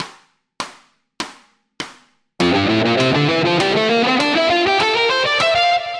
Перед вами фа-мажорная гамма.
Аудио (100 УВМ)